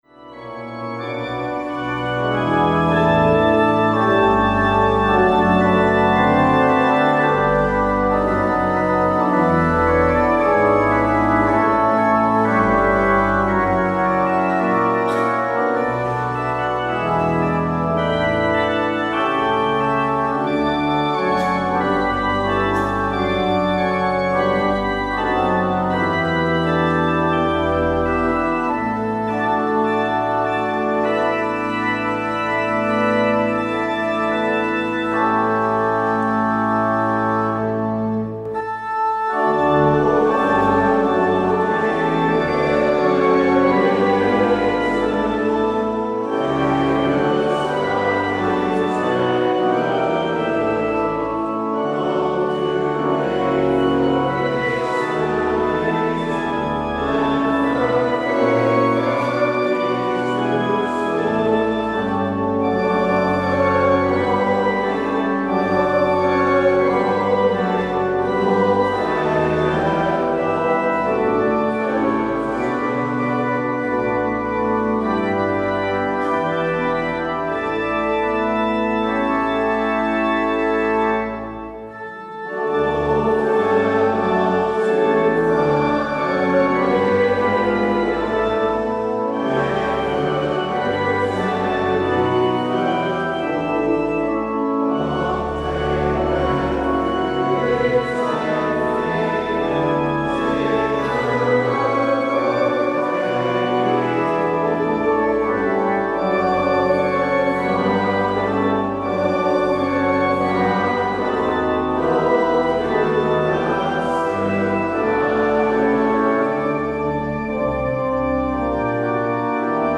De voorganger vandaag is Mgr. Jan Hendriks uit Haarlem / Amsterdam.